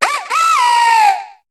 Cri d'Efflèche dans Pokémon HOME.